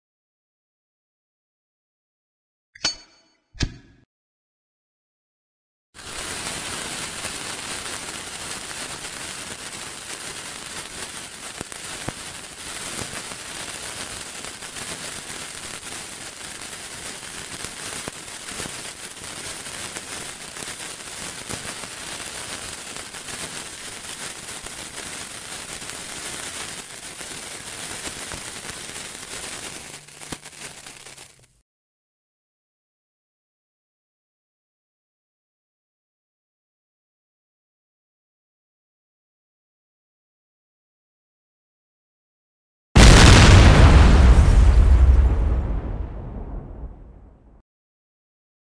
Tiếng Bom Nổ và tiếng Dây cháy chậm cháy
Thể loại: Đánh nhau, vũ khí
Description: Tiếng bom nổ, âm thanh vụ nổ lớn, tiếng mìn phát nổ, cùng với tiếng dây cháy chậm, âm thanh ngòi nổ bén lửa, hoặc tiếng lửa cháy lan qua kíp nổ, tiếng dây cháy lẹt xẹt, âm thanh nhỏ nhưng căng thẳng như tiếng nổ lửa cháy lan dọc theo dây ngòi nổ, tạo cảm giác đếm ngược, báo hiệu hiểm họa sắp xảy ra. Sau vài giây hồi hộp là một tiếng nổ lớn, dội vang, kèm theo âm rung chấn, tiếng mảnh vụn bay và sóng xung kích, tái hiện hoàn hảo một cảnh bom/mìn phát nổ cực mạnh...
tieng-bom-no-va-tieng-day-chay-cham-chay-www_tiengdong_com.mp3